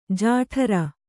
♪ jāṭhara